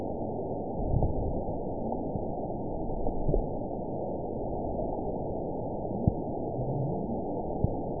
event 920874 date 04/12/24 time 23:30:07 GMT (1 year, 2 months ago) score 9.00 location TSS-AB05 detected by nrw target species NRW annotations +NRW Spectrogram: Frequency (kHz) vs. Time (s) audio not available .wav